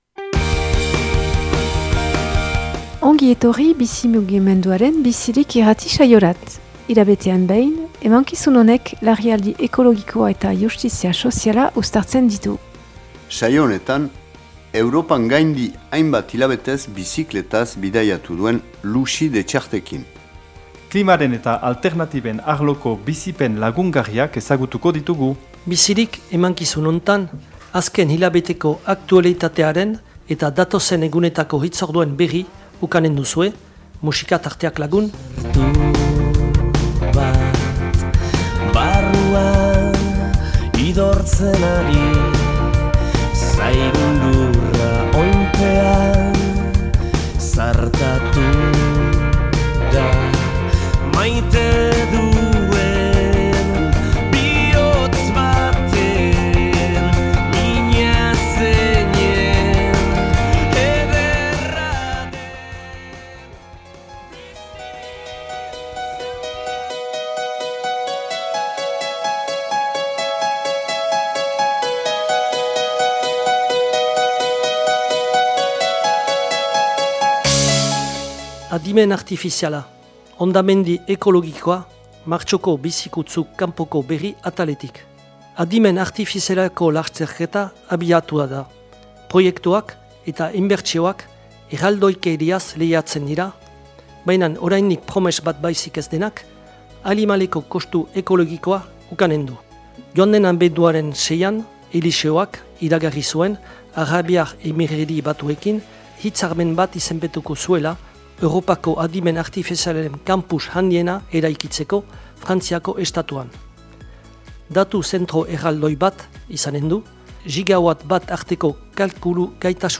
#124 Bizirik irrati saioa